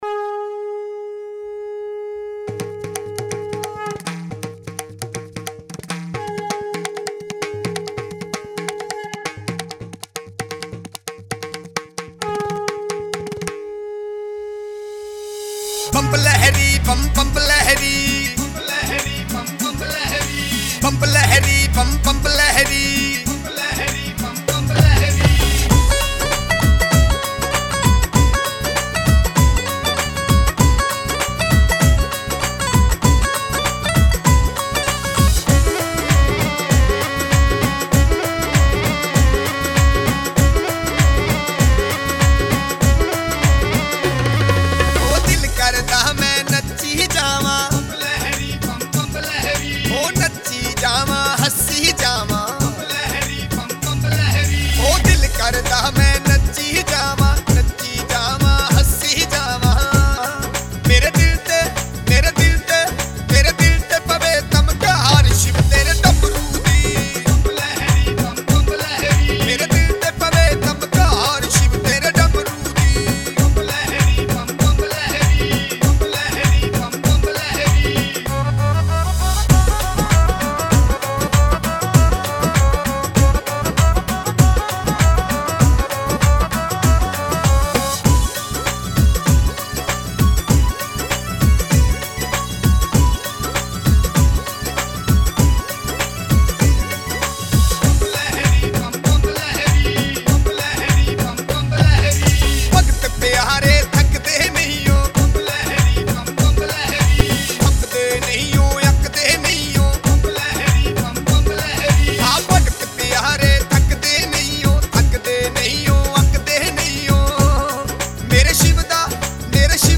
Category: Bhakti Sangeet